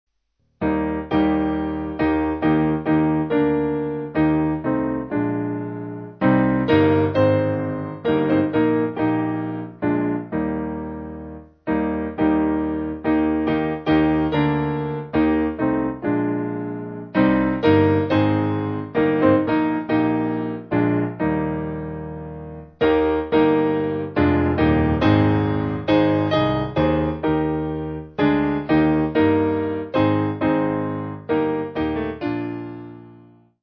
Simple Piano
(CM)   3/Eb